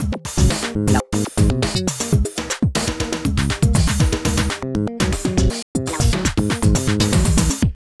And a few crazy loops, mostly done with random kits and functions.